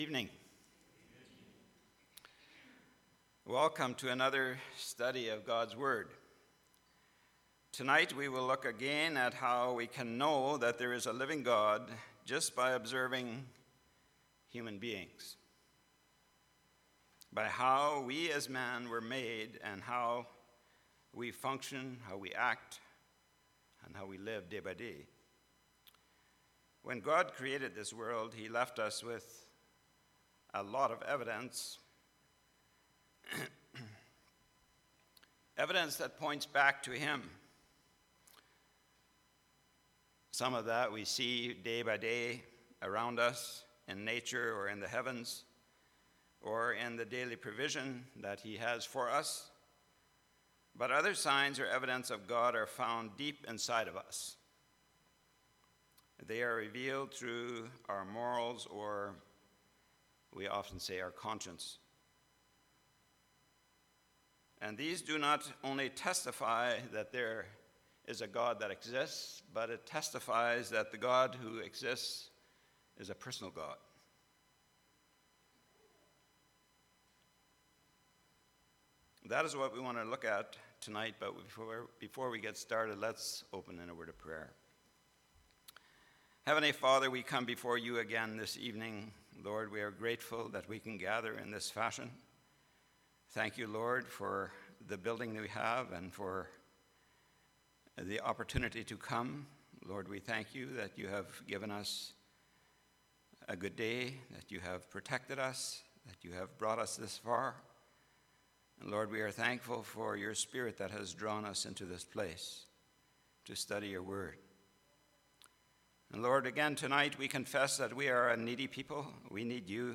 Church Bible Study – Apologetics